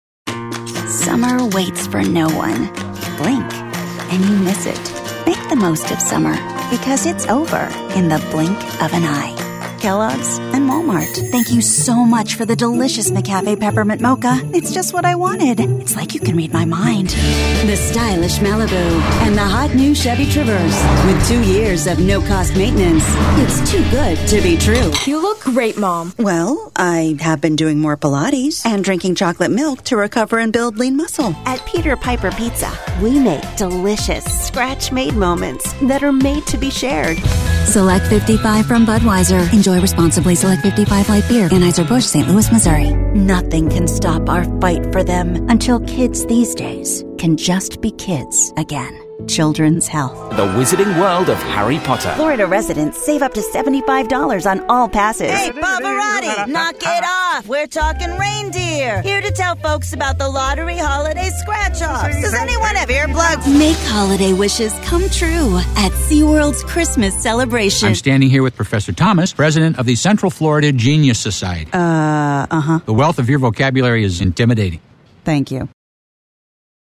Versatile and seasoned voice.
announcer, caring, character, confident, conversational, cool, friendly, genuine, high-energy, mother, raspy, real, retail, sincere, smooth, thoughtful, upbeat